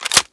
plugIn.wav